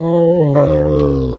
bdog_die_0.ogg